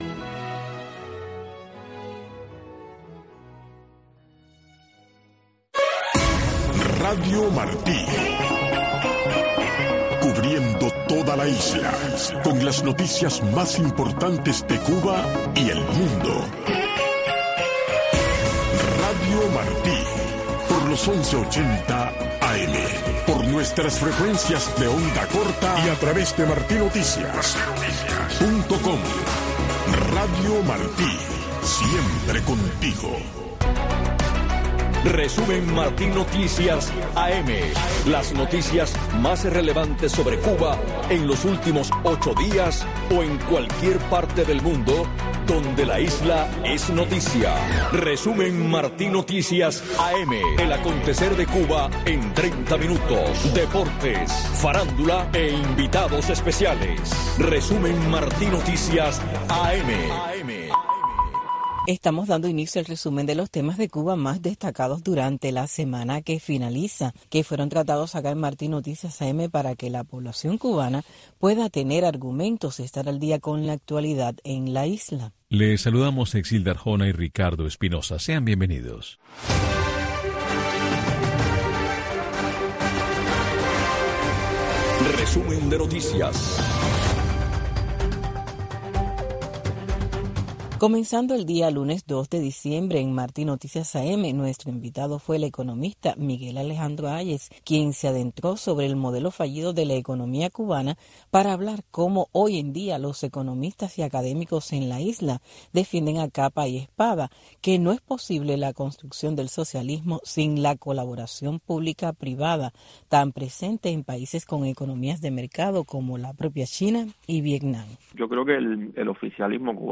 Un resumen ágil y variado con las noticias más relevantes que han ocurrido en Cuba en los últimos 8 días o en cualquier parte del mundo donde un tema sobre la isla es noticia, tratados con invitados especiales. Media hora de información, deportes y farándula.